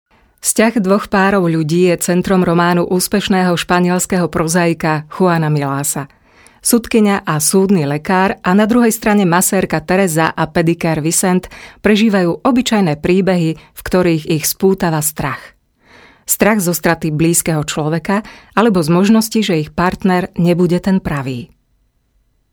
Professionelle slowakische Sprecherin für TV / Rundfunk / Industrie / Werbung.
Sprechprobe: Industrie (Muttersprache):
Professionell slovakian female voice over artist